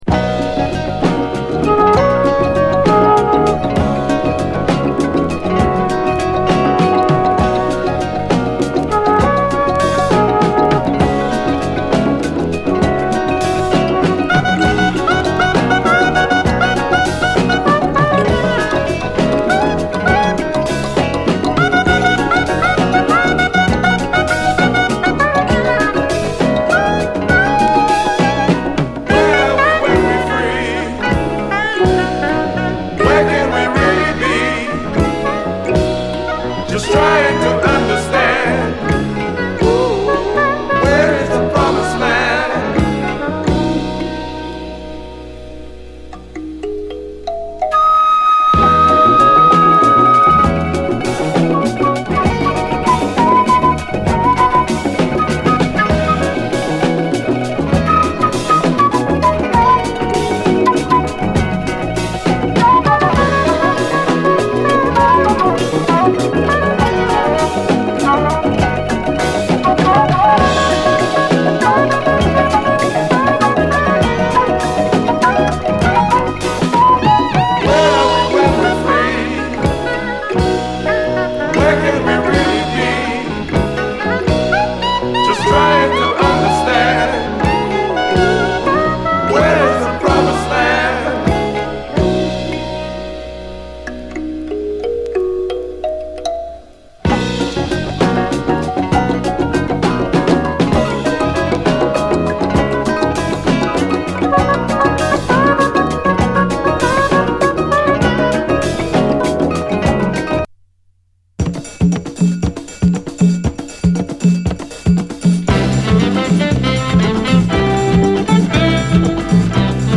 SOUL, 70's～ SOUL, LATIN, CARIBBEAN
溢れるヒューマニティーで心揺さぶるカリビアン・メロウ・グルーヴ
ハッピーなカリビアン・ダンサー